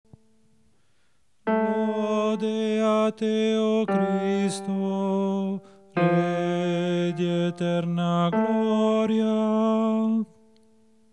BASSI
CDV101-Lode-a-Te-o-Cristo-Bassi-Acclamazione-1^-melodia.mp3